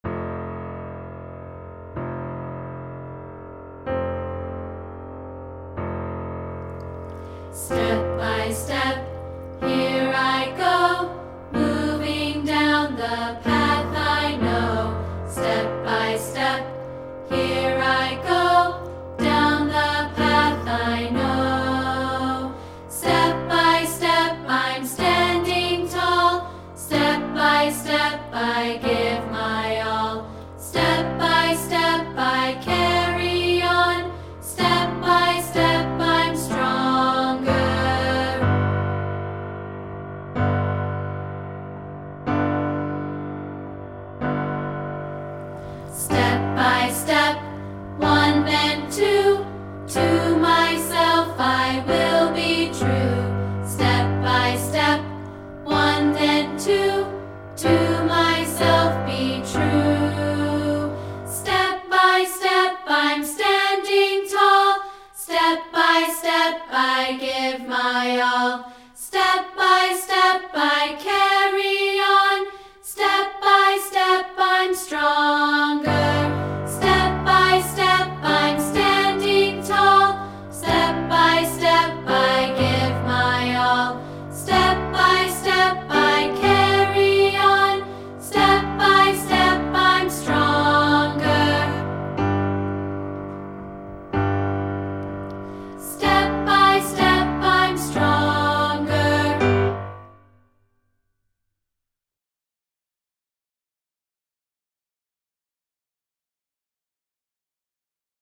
catchy tune
This is an audio track of part 2 isolated.